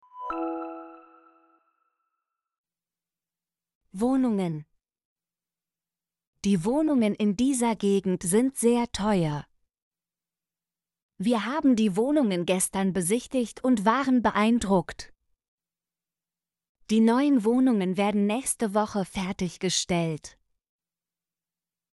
wohnungen - Example Sentences & Pronunciation, German Frequency List